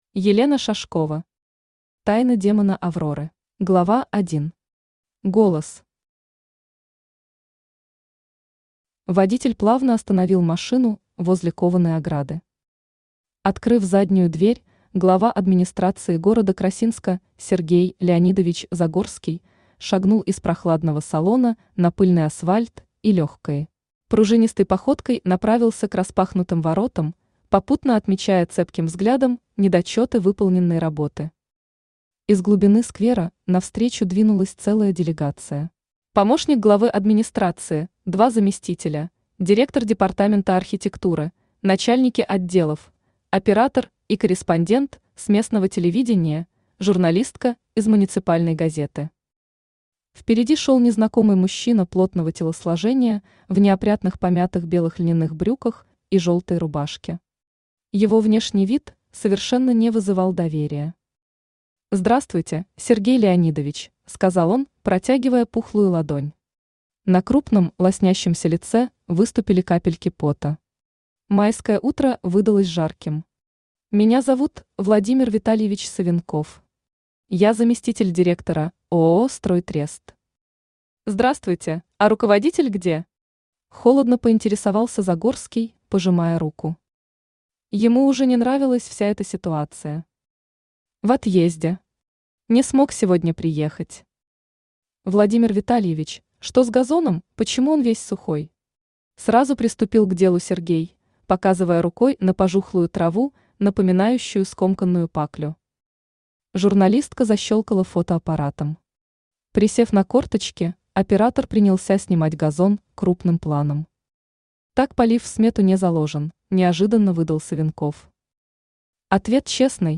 Аудиокнига Тайна демона Авроры | Библиотека аудиокниг
Aудиокнига Тайна демона Авроры Автор Елена Шашкова Читает аудиокнигу Авточтец ЛитРес.